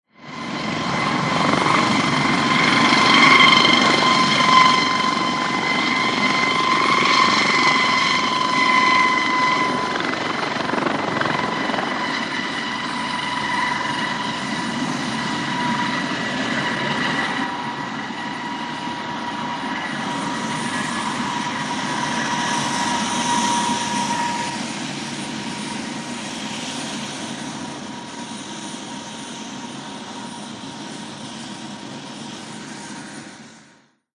HELICOPTER JET: EXT: Hovering, away. Pulsating jet engine whine.